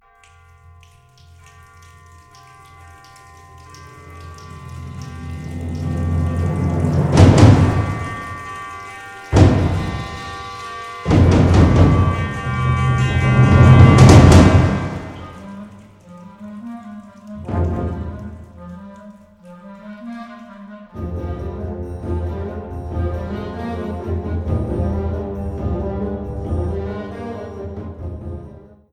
Categorie Harmonie/Fanfare/Brass-orkest
Subcategorie Concertmuziek
Bezetting Ha (harmonieorkest)